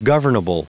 Prononciation du mot governable en anglais (fichier audio)
Prononciation du mot : governable